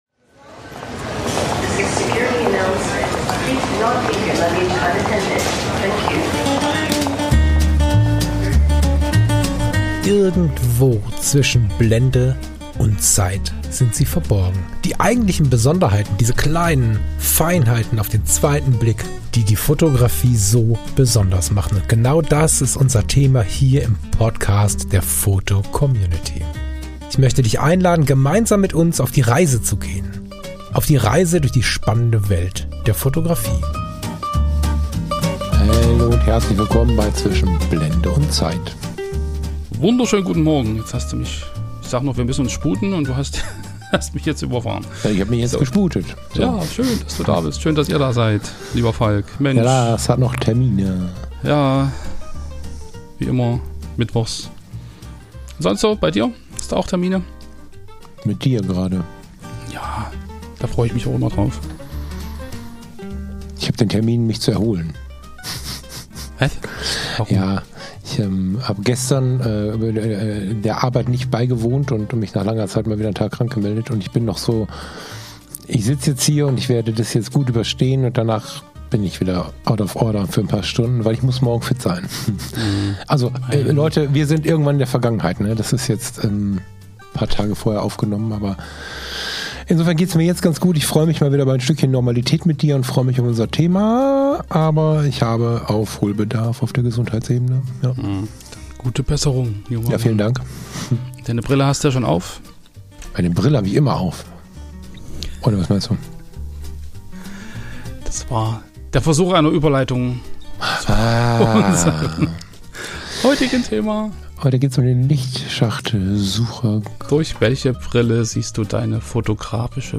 Eine entspannte Unterhaltung über Sucher, Wahrnehmung und die Lust, Fotografie einfach mal anders auszuprobieren.